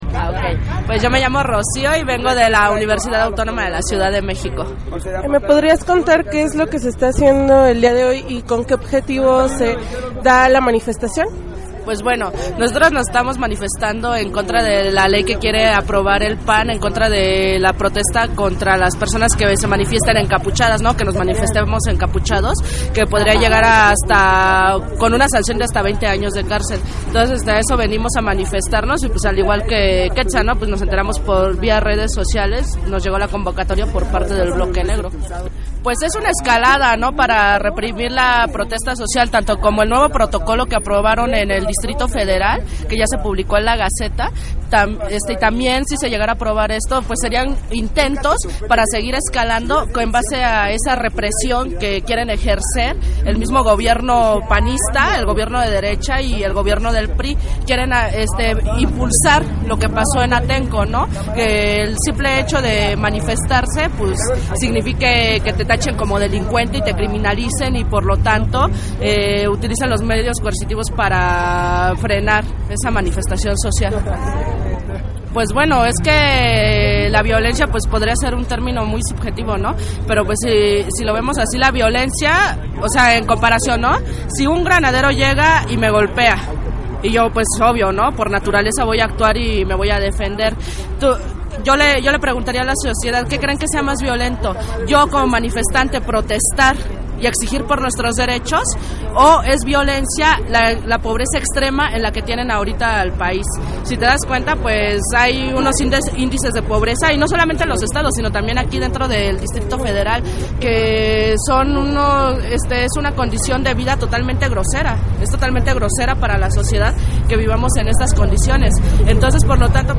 En entrevista con Regeneración Radio algunos de ellos hacen mención del porqué no estar de acuerdo.
Entrevista_1.mp3